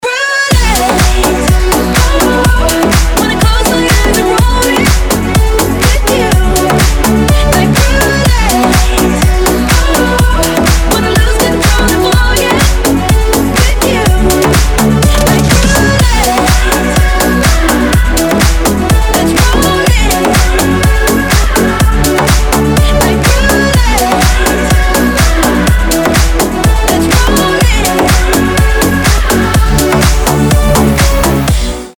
громкие
женский вокал
dance
club